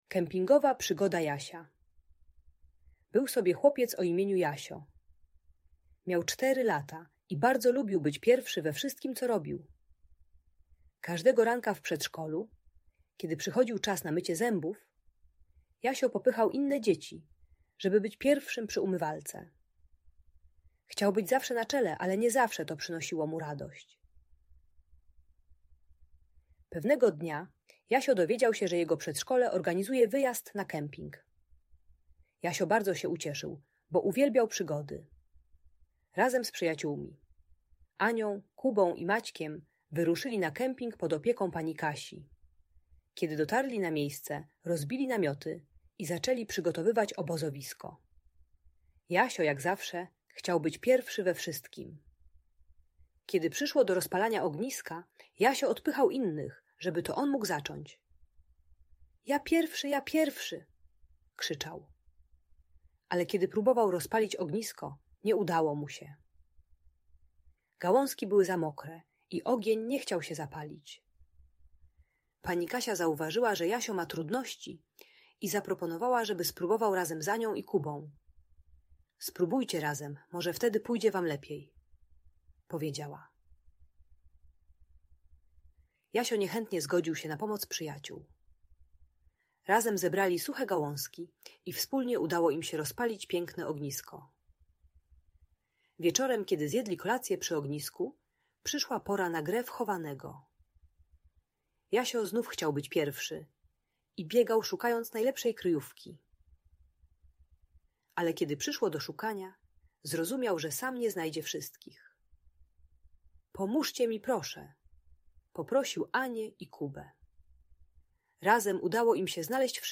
Kempingowa Przygoda Jasia - Opowieść o Współpracy - Audiobajka